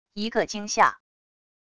一个惊吓wav音频